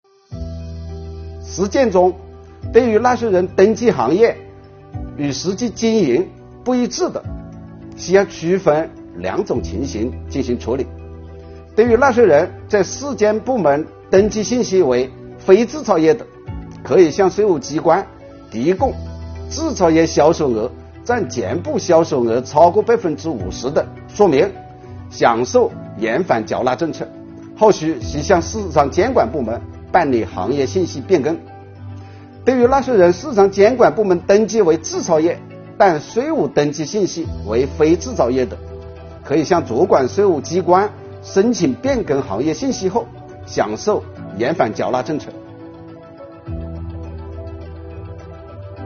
本期课程国家税务总局征管和科技发展司副司长付扬帆担任主讲人，对制造业中小微企业缓缴税费政策解读进行详细讲解，确保大家能够及时、便利地享受政策红利。